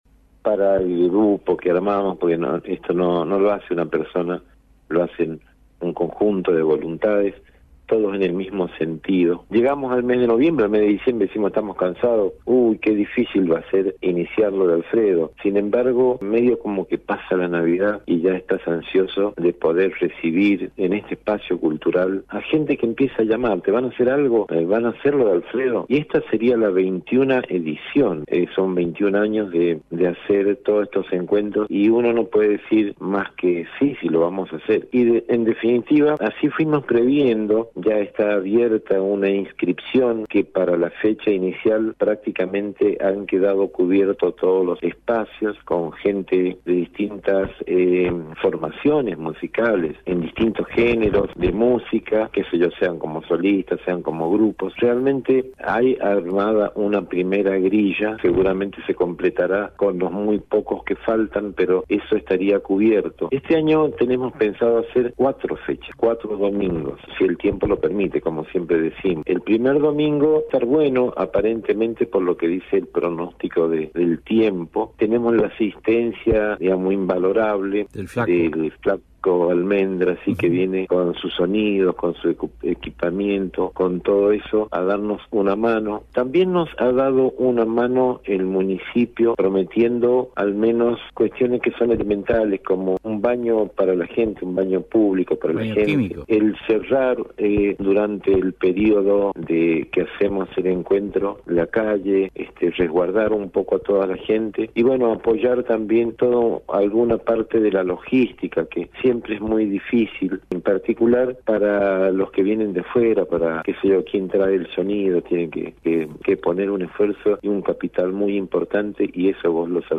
dialogó con el programa Piedra Libre